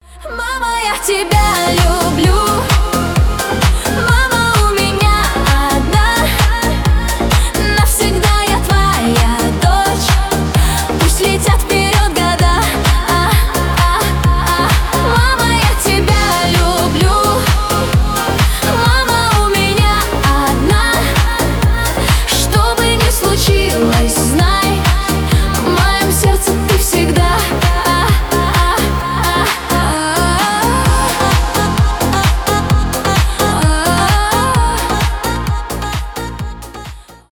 поп
Танцевальные рингтоны